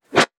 metahunt/weapon_bullet_flyby_14.wav at master
weapon_bullet_flyby_14.wav